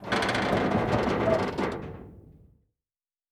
Metal Foley Creak 1.wav